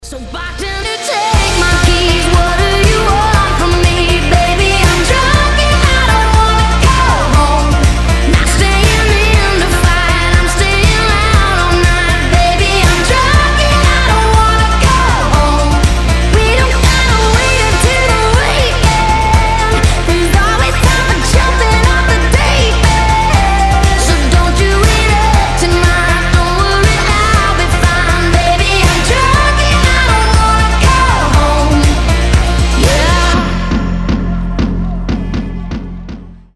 • Качество: 320, Stereo
Pop Rock
озорные
country rock